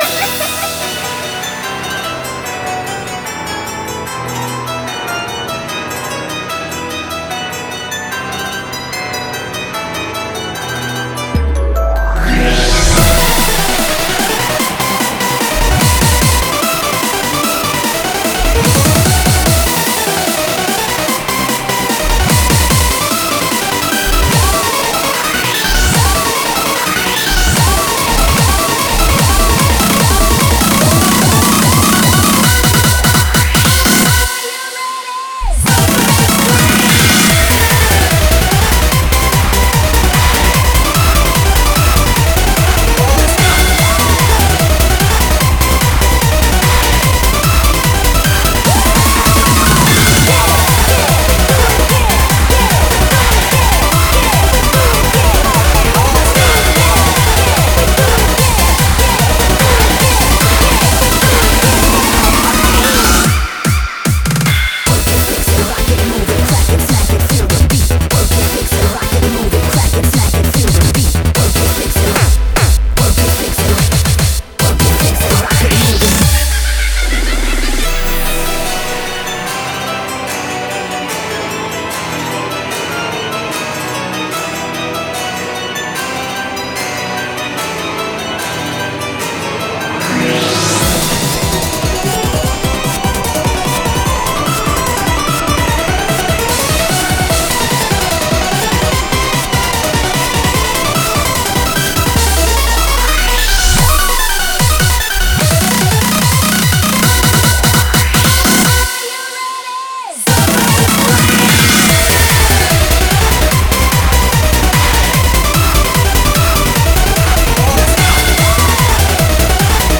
BPM148
MP3 QualityMusic Cut